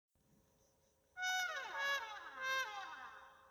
Sad Fail Efecto de Sonido Descargar
Sad Fail Botón de Sonido